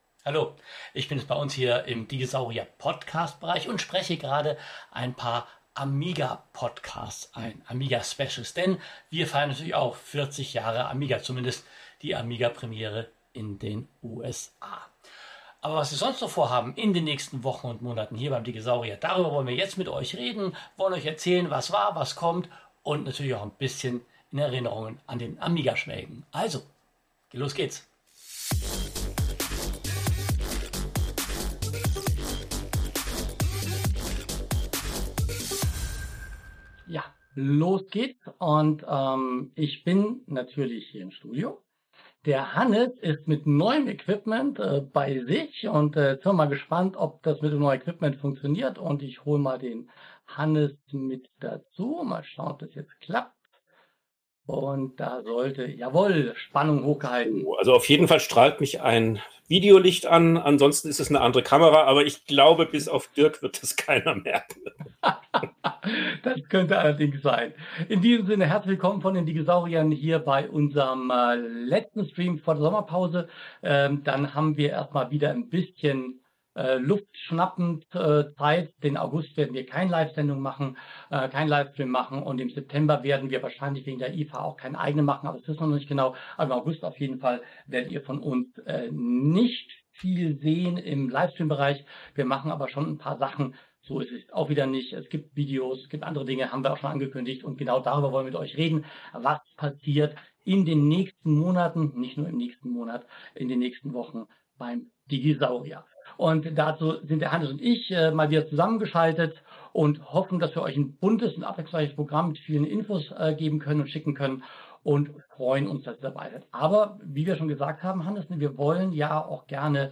Unser letzter Livestream "Digitaler Monat" als Podcast vor der Sommerpause!